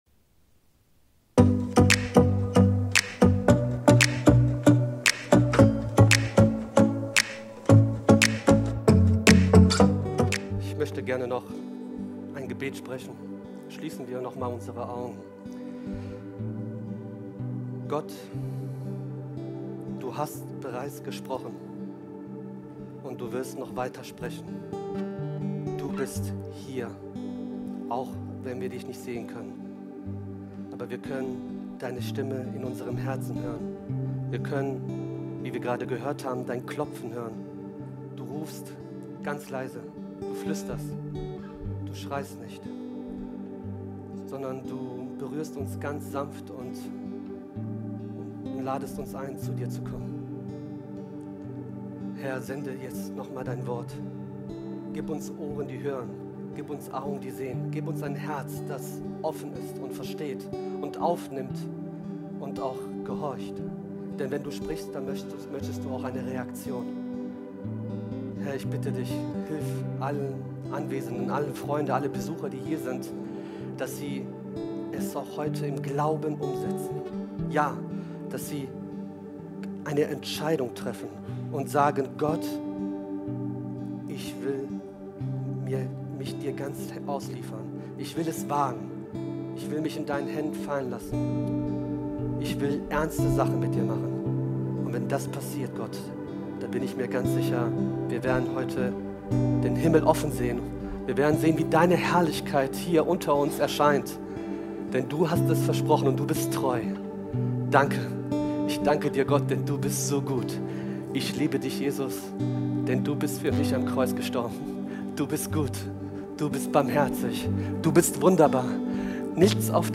Video und MP3 Predigten
Kategorie: Sonntaggottesdienst Predigtserie: Fremde neue Welt